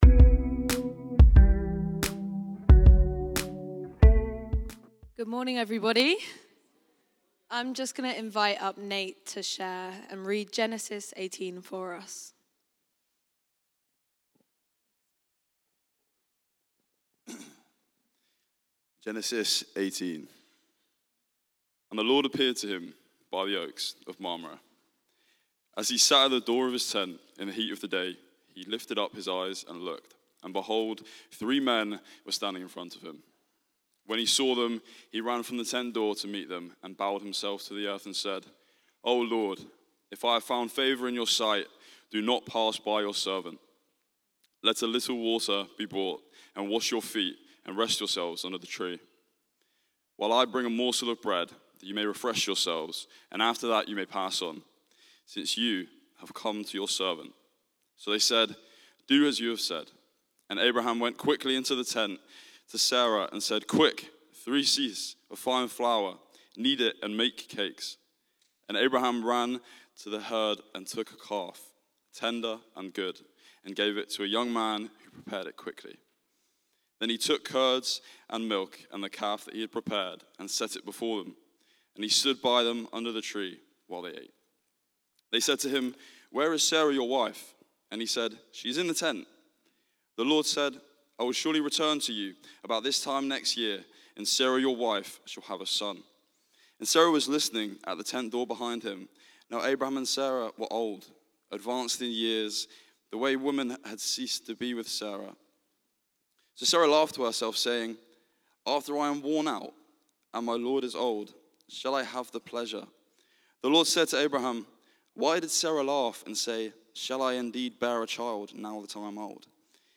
Rediscover Church Newton Abbot | Sunday Messages